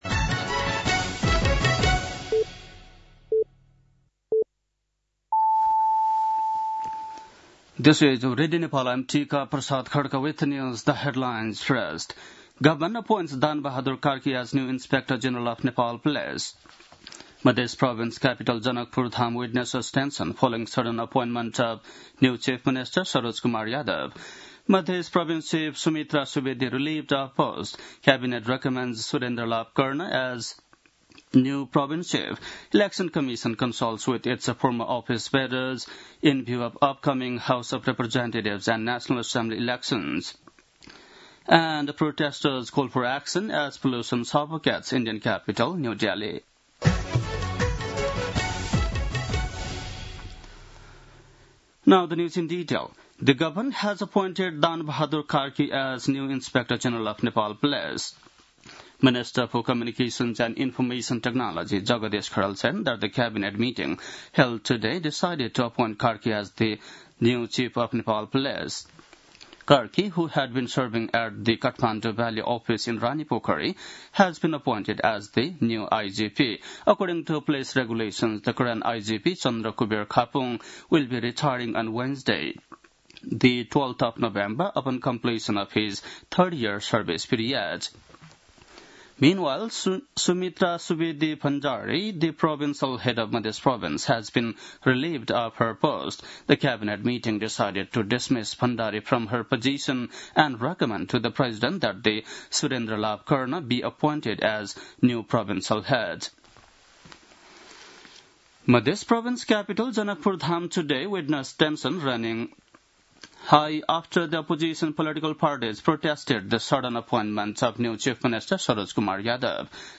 बेलुकी ८ बजेको अङ्ग्रेजी समाचार : २४ कार्तिक , २०८२
8-pm-english-news-7-24.mp3